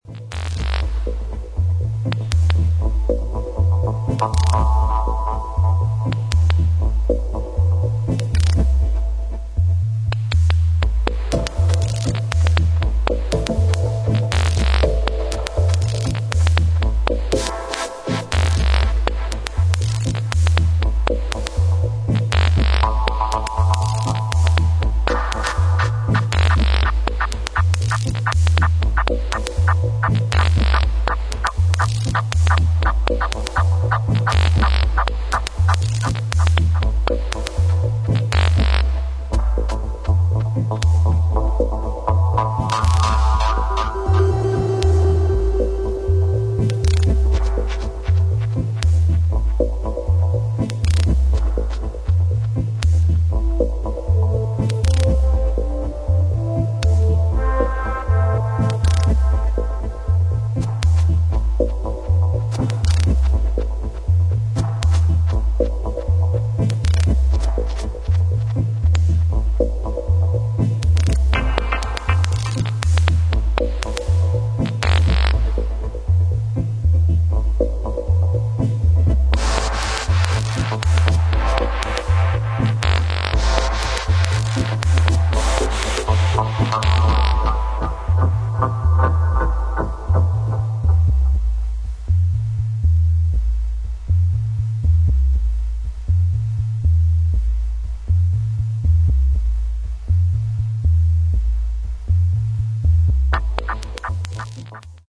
両トラックともキックが入らないエクスペリメンタルなディープ ダブ・テクノ作品です。